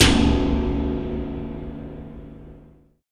SI2 SPROSCH.wav